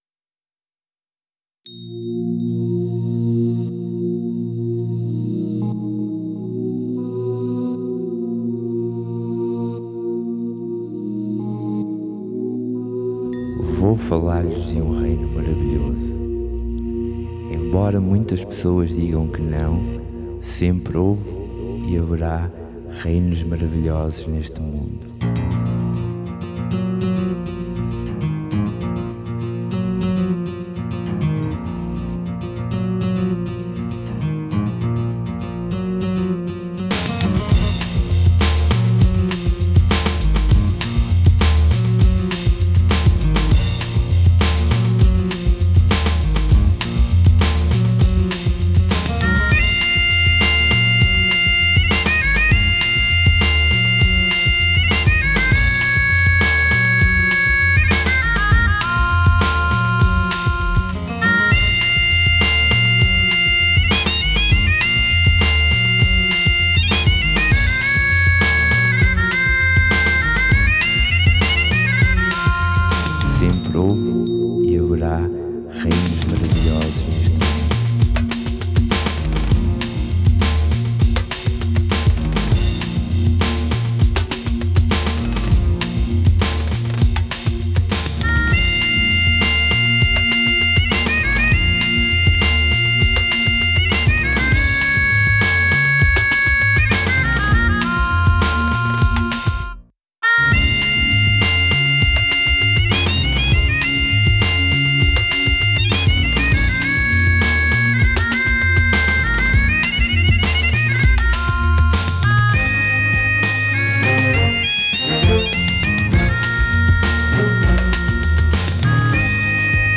voice
instrumental